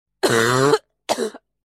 cough2.ogg